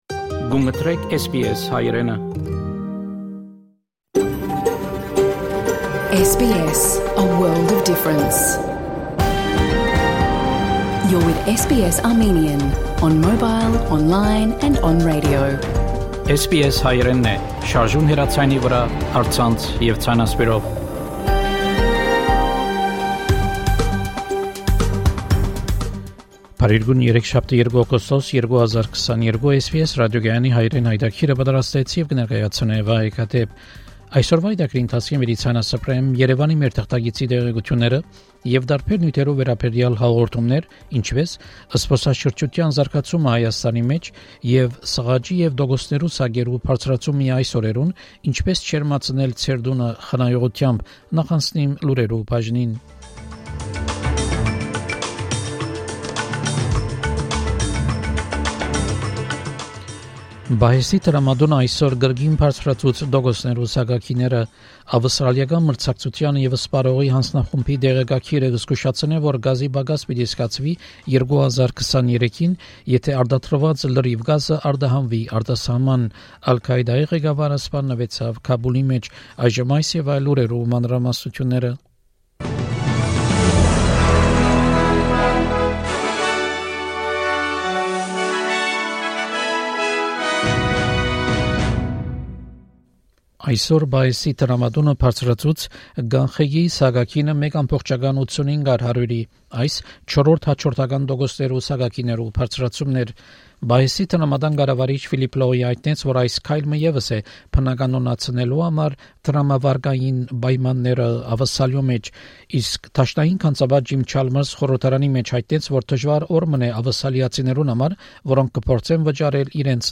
SBS Armenian news bulletin – 2 August 2022
SBS Armenian news bulletin from 2 August 2022 program.